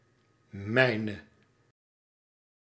Ääntäminen
Synonyymit mijn m'n Ääntäminen Tuntematon aksentti: IPA: /ˈmɛi̯nə/ Haettu sana löytyi näillä lähdekielillä: hollanti Käännöksiä ei löytynyt valitulle kohdekielelle.